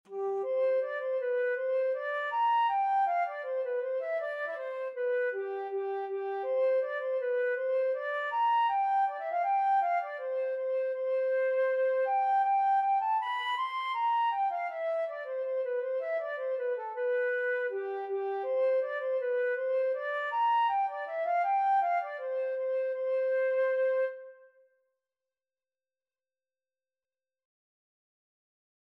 2/4 (View more 2/4 Music)
C major (Sounding Pitch) (View more C major Music for Flute )
Flute  (View more Intermediate Flute Music)
Traditional (View more Traditional Flute Music)